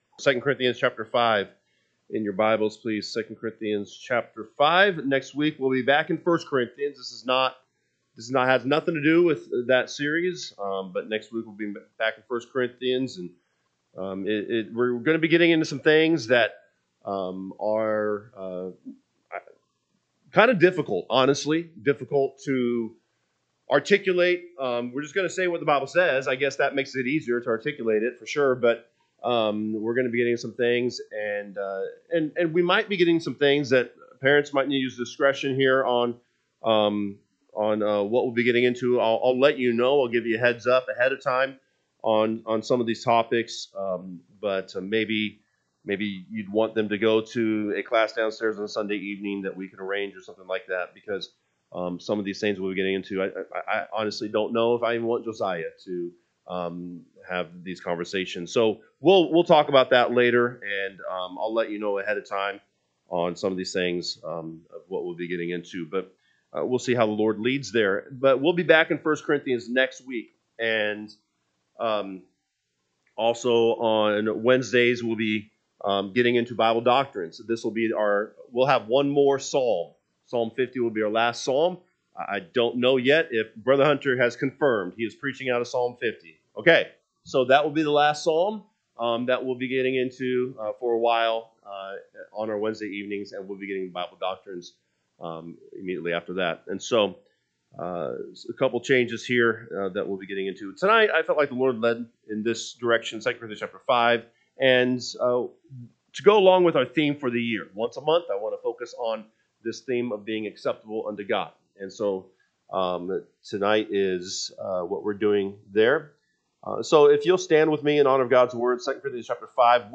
March 22, 2026 pm Service 2 Corinthians 5:1-9 (KJB) 5 For we know that if our earthly house of this tabernacle were dissolved, we have a building of God, an house not made with hands, eternal …